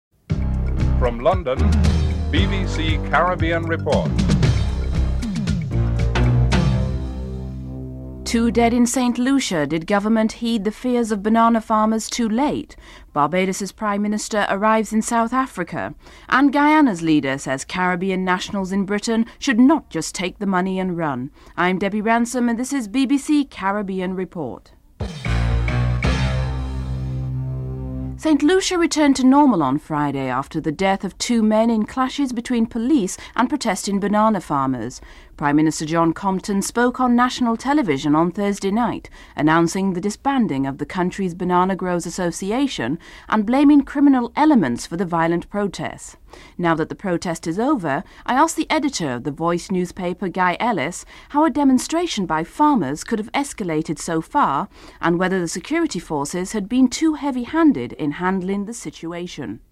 Headlines with anchor